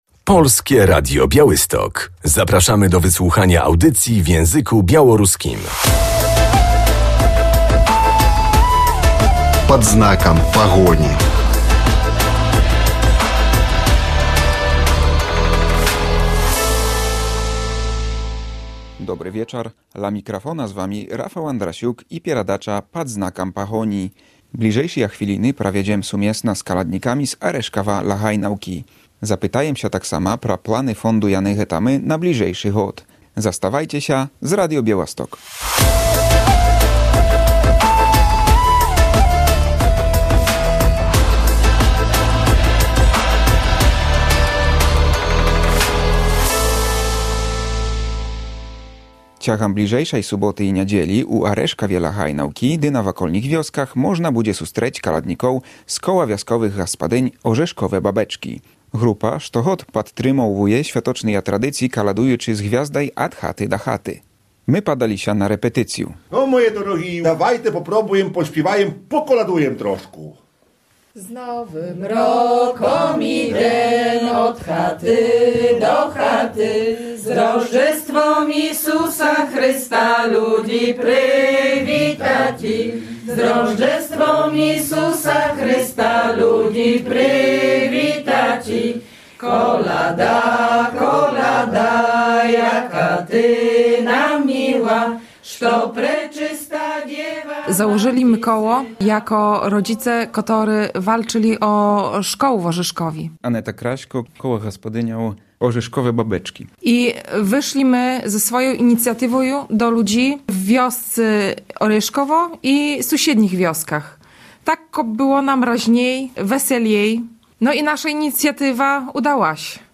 Kultywują tradycje kolędnicze w Orzeszkowie i okolicach. Koło Gospodyń Wiejskich „Orzeszkowe Babeczki” zaraz po świętach odwiedza z gwiazdą i bożonarodzeniowymi pieśniami mieszkańców okolicznych wsi. Zajrzymy na przygotowania do najbliższego kolędowania.